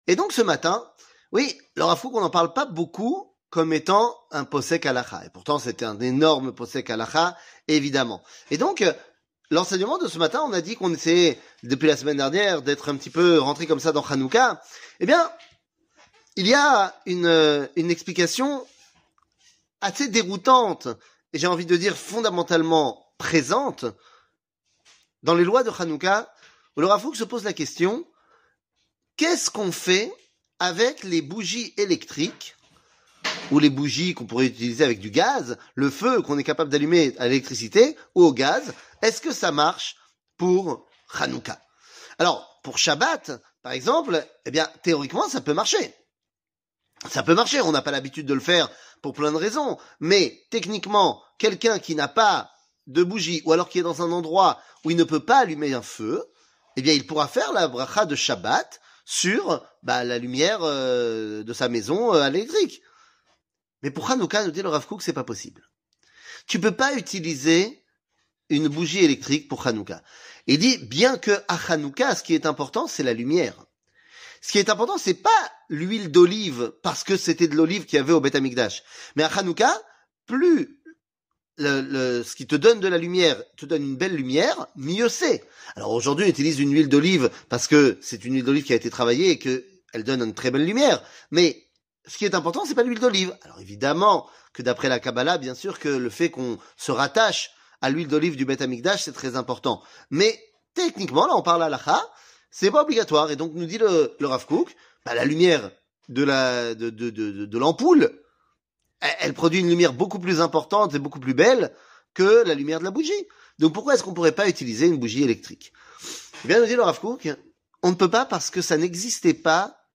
Rav Kook, Bougie electrique, lois sur Hanouka 00:04:30 Rav Kook, Bougie electrique, lois sur Hanouka שיעור מ 06 דצמבר 2022 04MIN הורדה בקובץ אודיו MP3 (4.12 Mo) הורדה בקובץ וידאו MP4 (8.87 Mo) TAGS : שיעורים קצרים